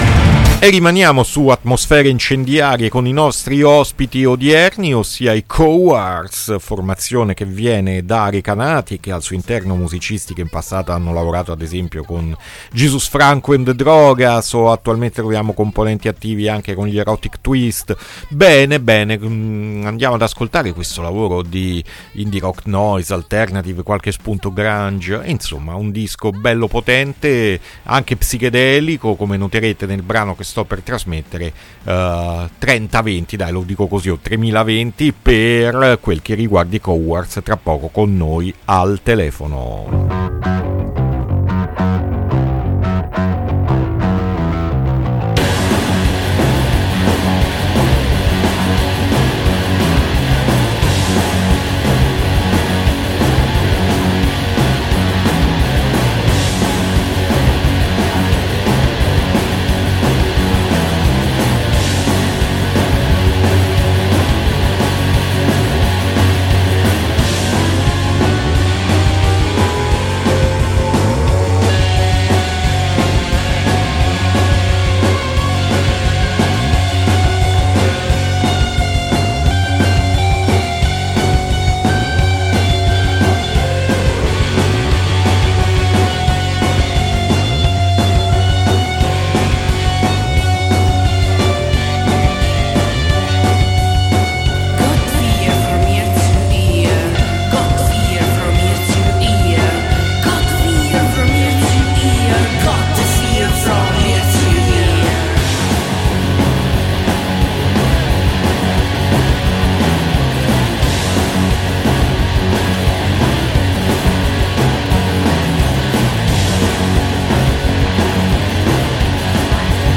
INTERVISTA COWARDS A MERCOLEDI' MORNING 5-3-2025